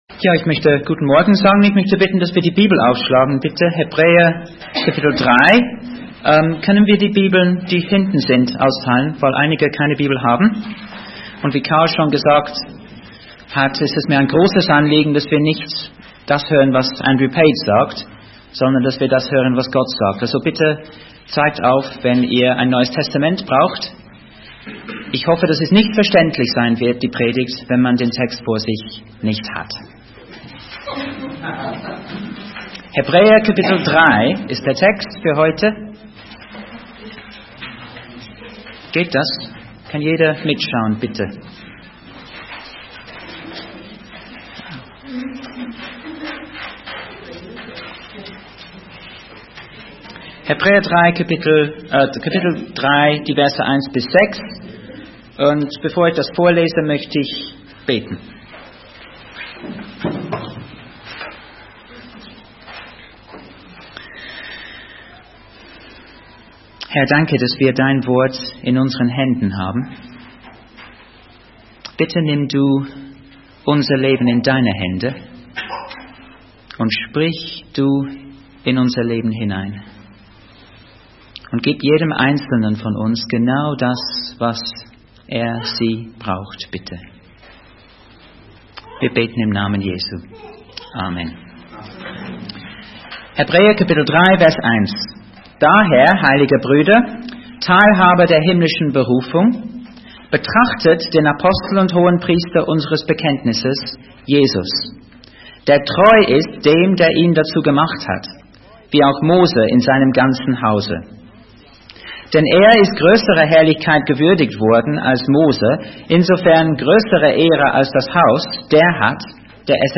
Wie kann ich Jesus leidenschaftlich nachfolgen? (Hebräer 3,1-6) Du findest Unterlagen für diese Predigt in dem Buch.
Seite 45 Anhören (niedrige Qualität, 4MB) Anhören